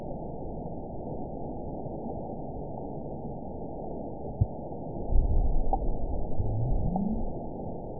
event 918099 date 05/04/23 time 00:33:43 GMT (2 years ago) score 9.36 location TSS-AB05 detected by nrw target species NRW annotations +NRW Spectrogram: Frequency (kHz) vs. Time (s) audio not available .wav